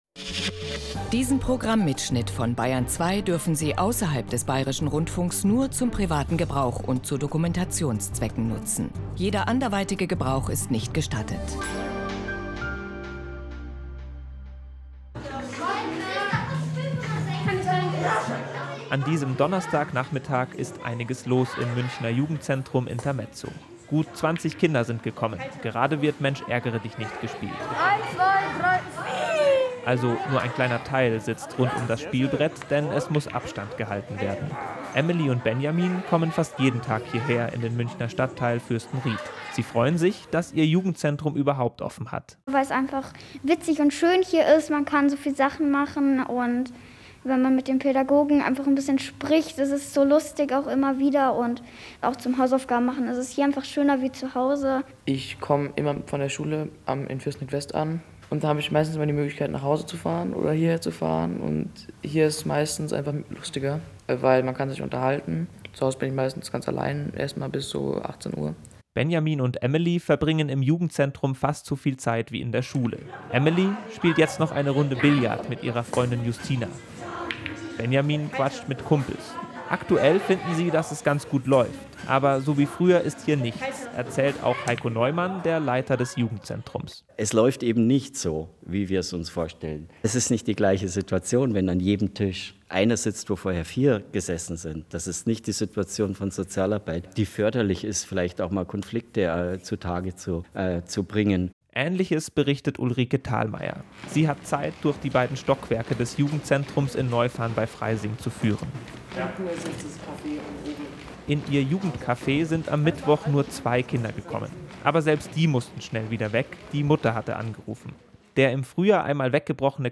BR-Radiobeitrag Jugendzentren – Intermezzo
BR-Radiobeitrag-Jugendzentren.MP3